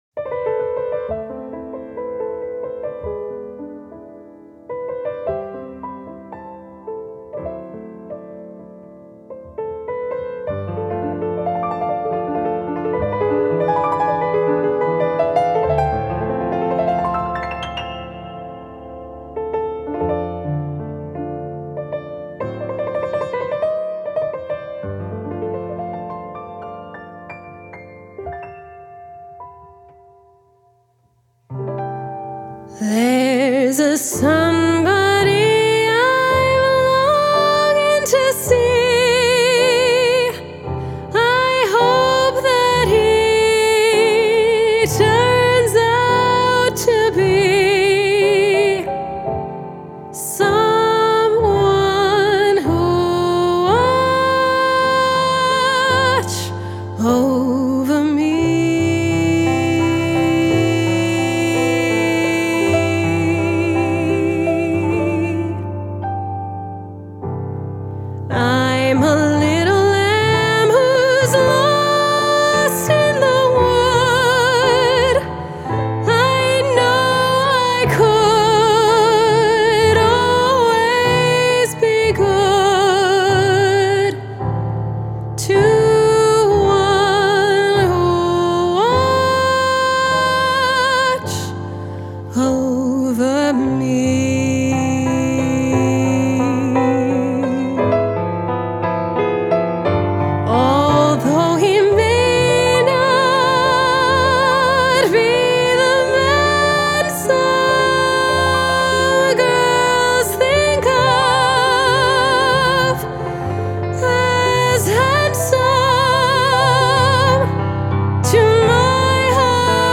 Crossover soprano